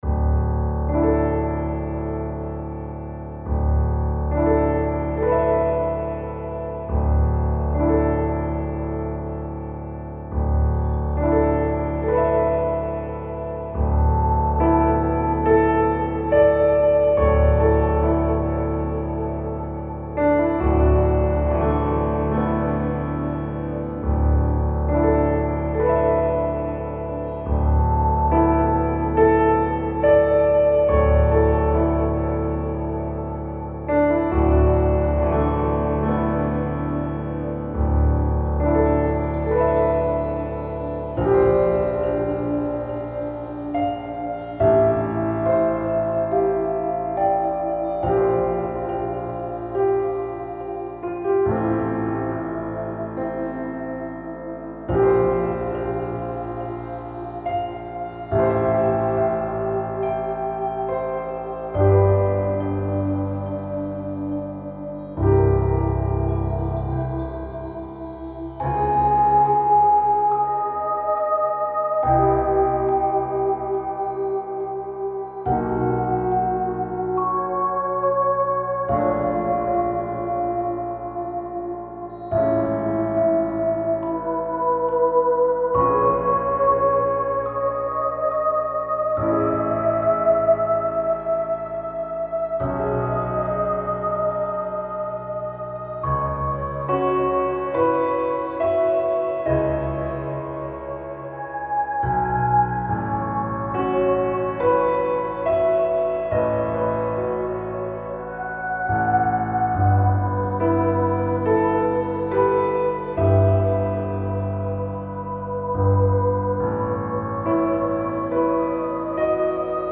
BGM
スローテンポ明るい穏やか